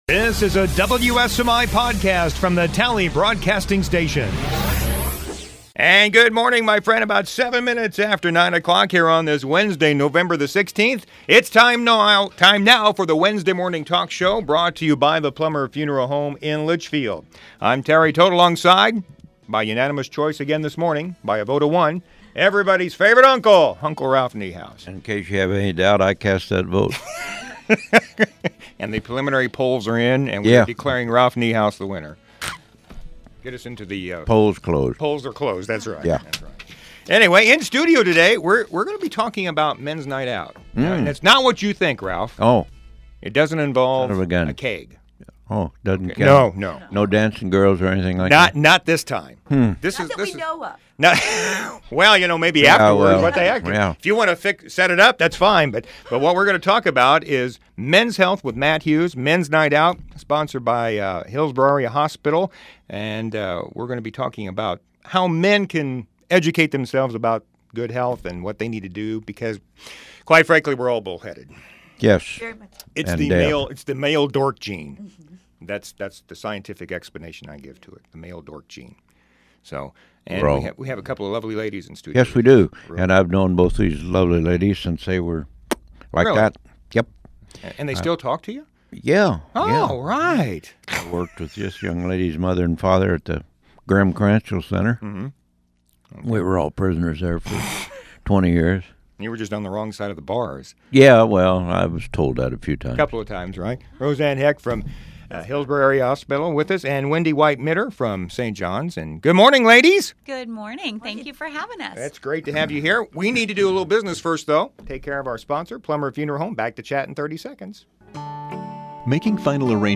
The Wednesday Morning Talk Show